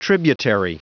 Prononciation du mot tributary en anglais (fichier audio)
Prononciation du mot : tributary